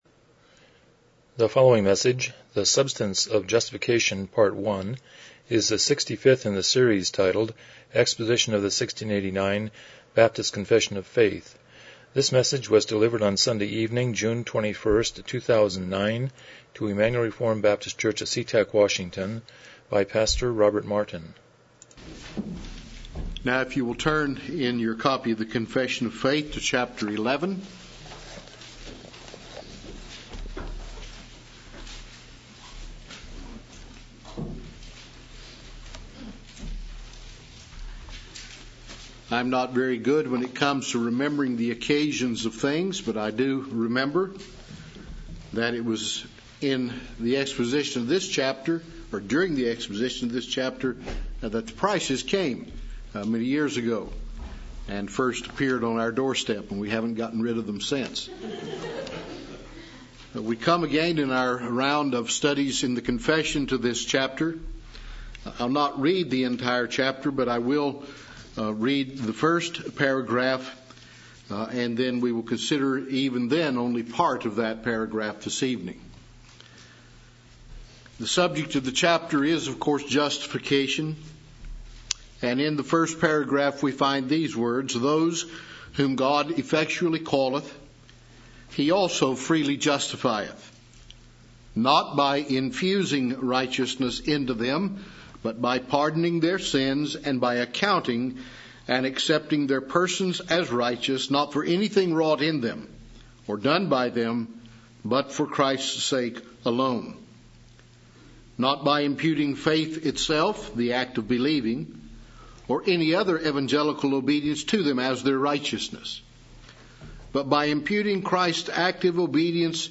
Series: 1689 Confession of Faith Service Type: Evening Worship « Who is Wise and Understanding Among You?